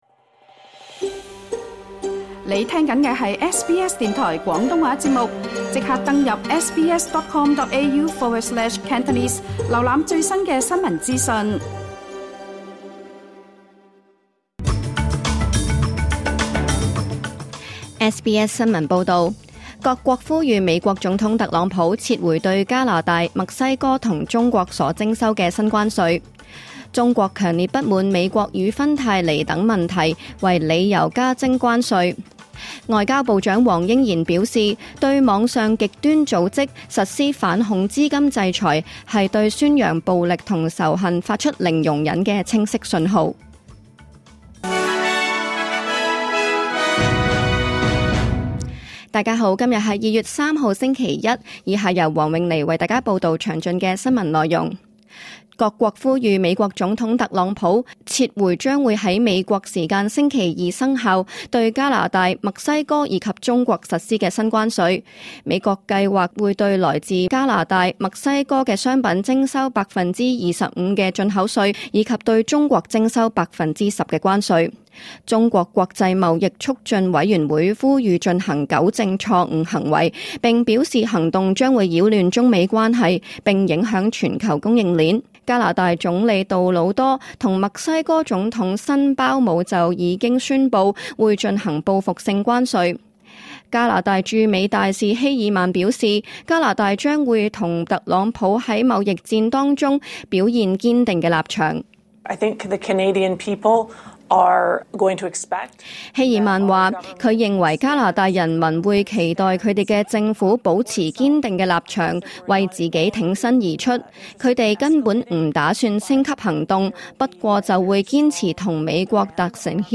2025 年 2月 3日 SBS 廣東話節目詳盡早晨新聞報道。